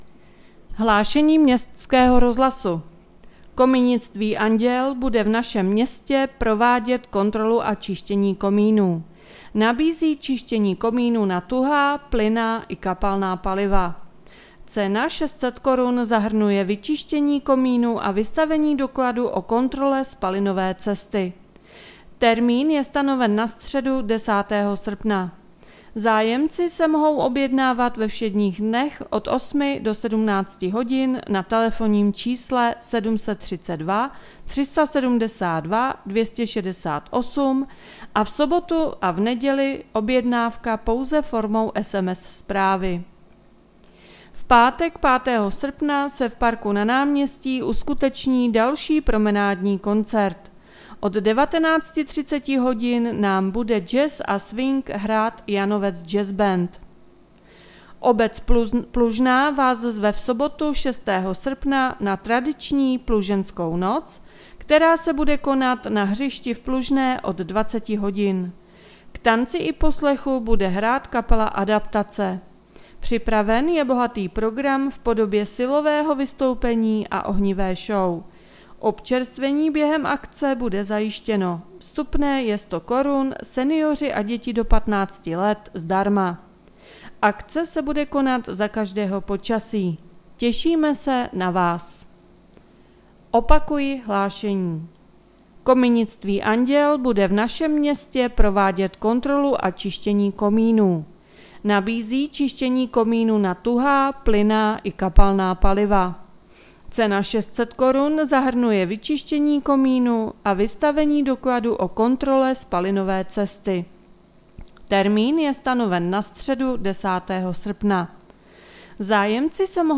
Hlášení městského rozhlasu 3.8.2022